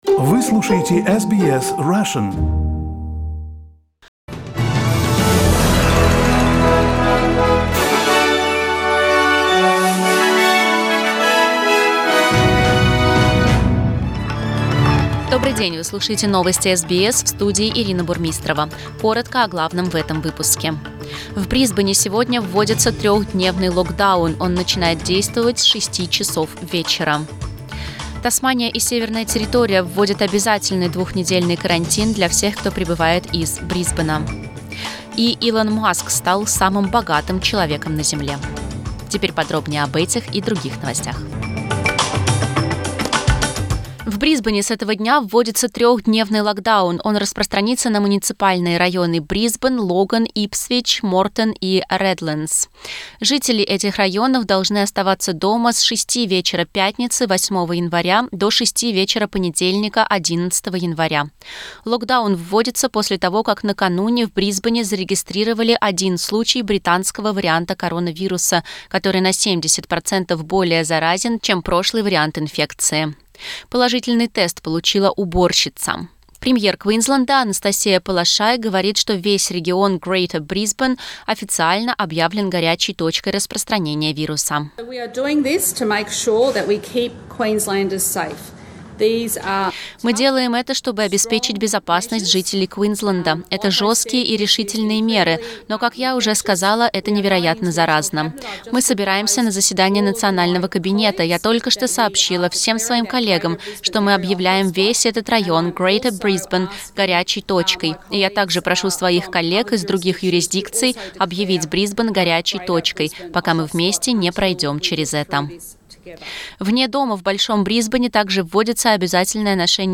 Новостной выпуск за 8 января